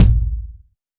DrKick70.wav